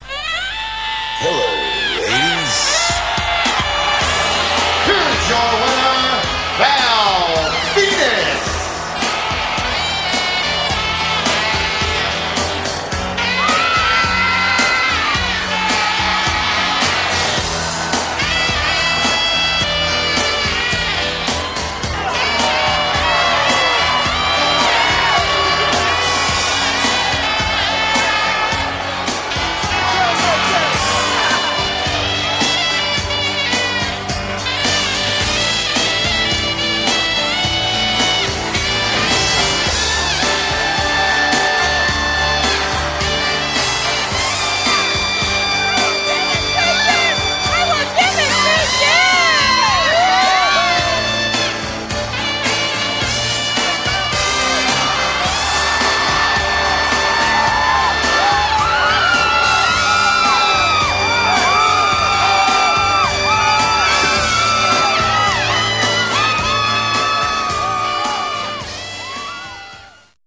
Enterance Music